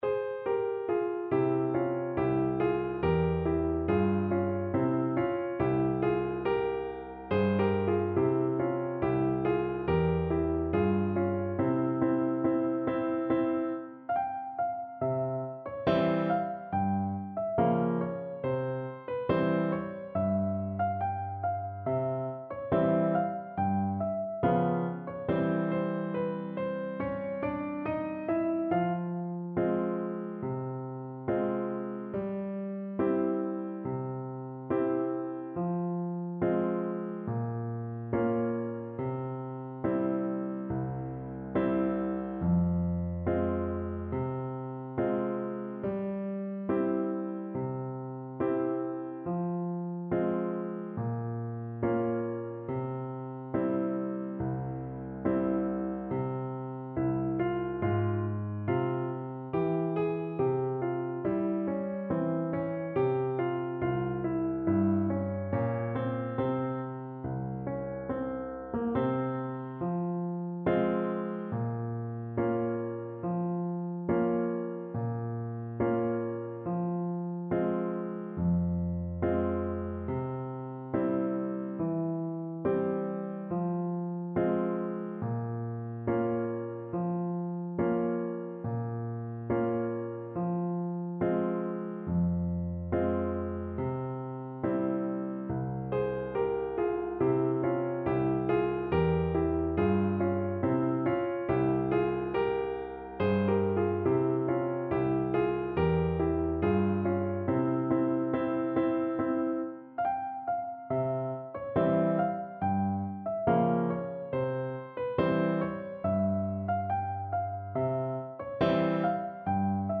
Slow =c.96
Clarinet pieces in C minor